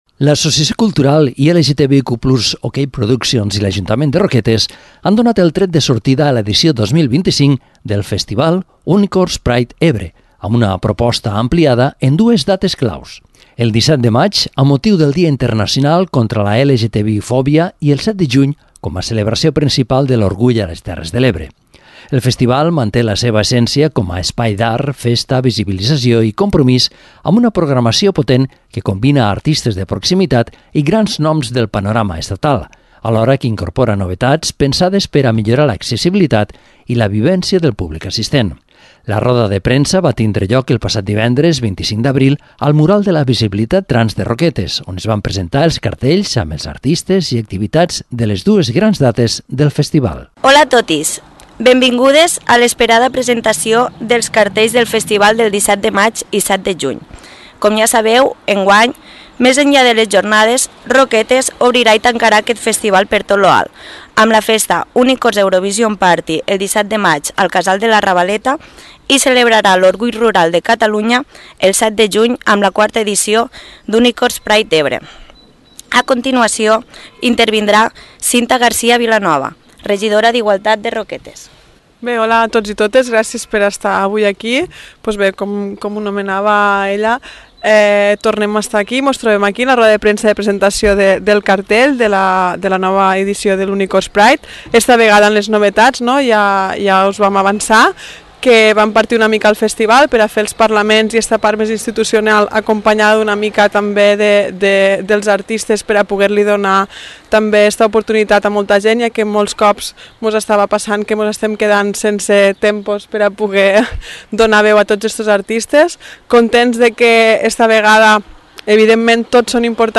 La roda de premsa ha tingut lloc al Mural de la Visibilitat Trans de Roquetes on s’han presentat els cartells amb els artistes i activitats de les dues dates grans del festival.